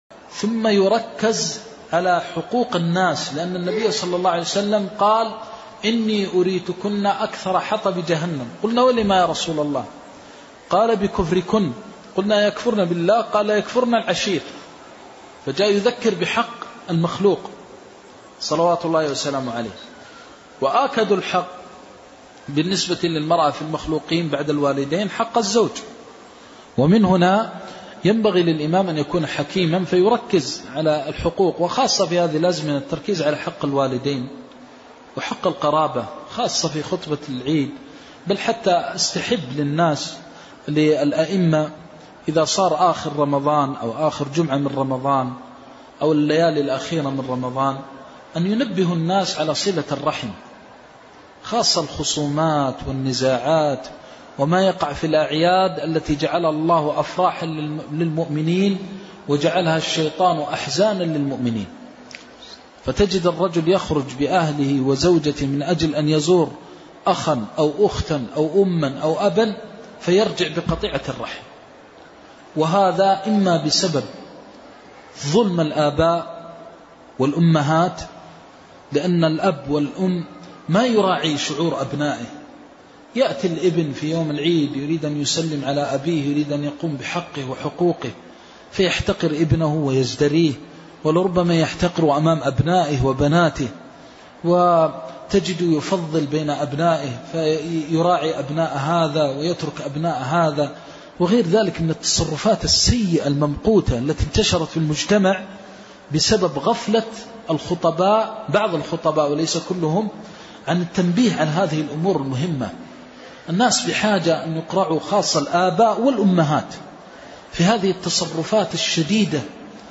جودة عالية.